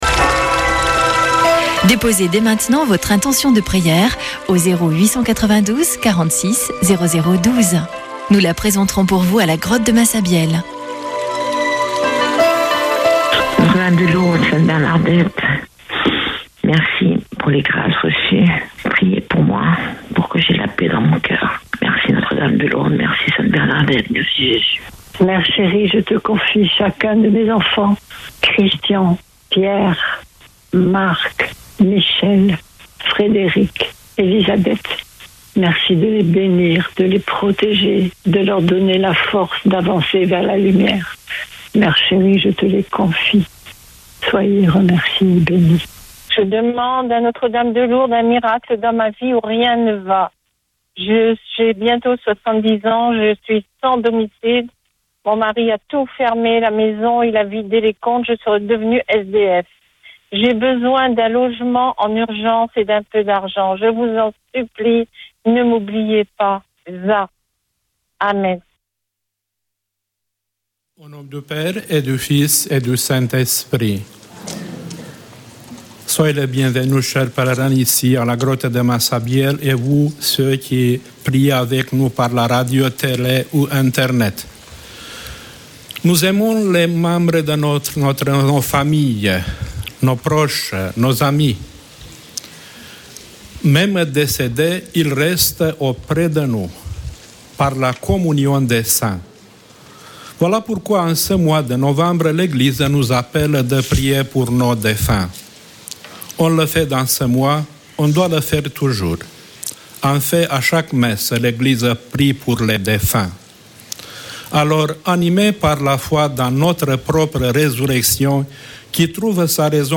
Chapelet de Lourdes du 19 nov.